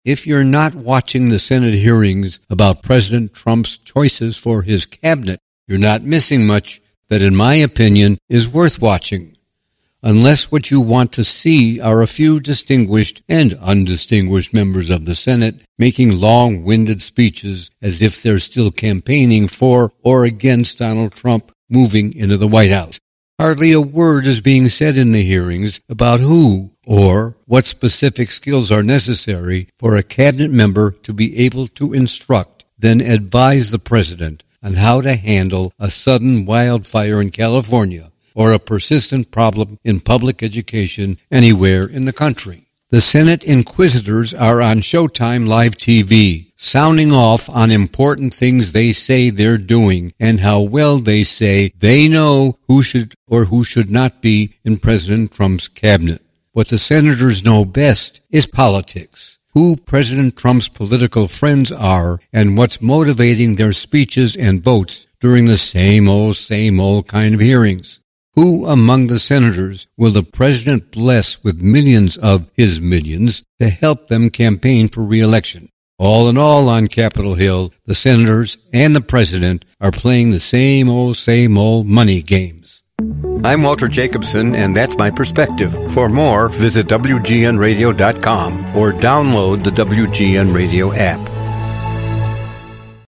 … continue reading 91 epizódok # Walter # Jacobson # Perspective # Plus # Chicago # Politics # Legendary # Anchor # Anchorman # Channel # Commentary # News # Journalist # WGN Plus # WGN # CBS # WBBM # Report